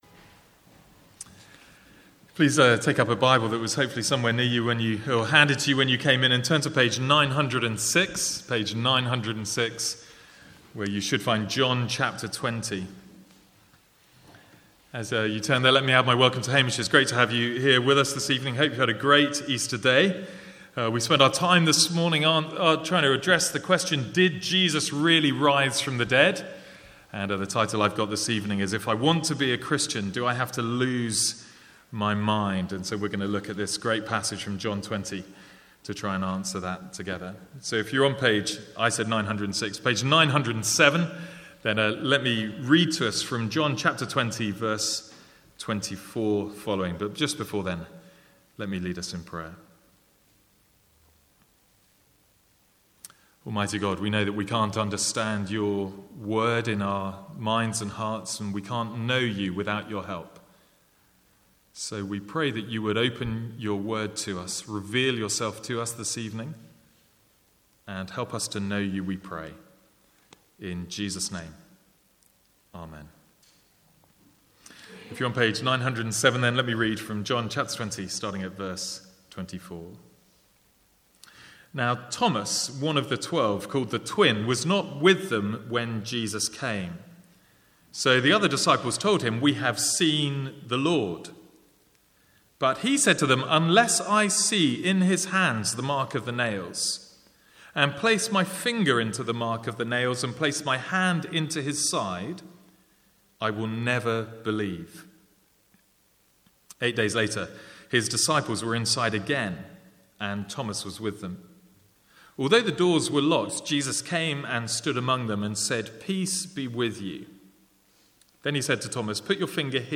Sermons | St Andrews Free Church
Download Download Sermon Notes John 20.24-31 Handout.pdf From the evening service on Easter Sunday 2015.